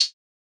TS HiHat_1.wav